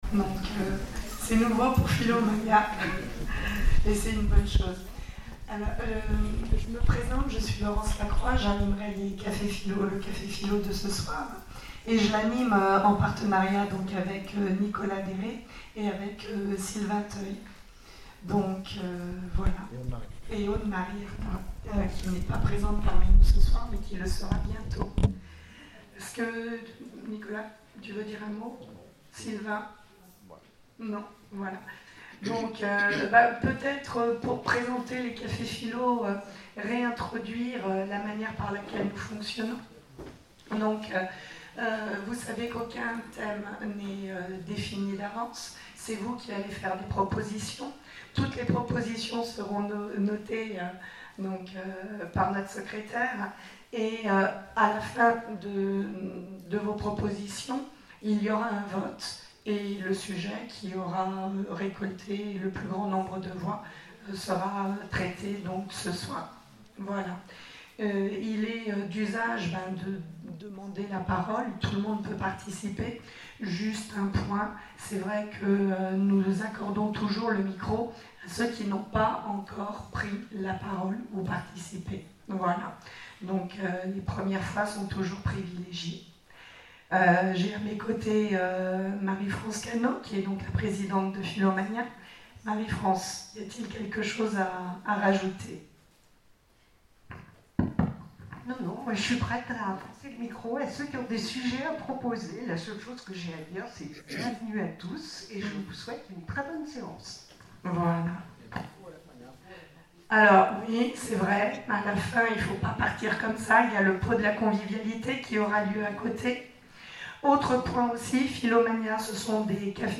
Conférences et cafés-philo, Orléans
CAFÉ-PHILO PHILOMANIA Que penser des processus d’évaluation dans notre société?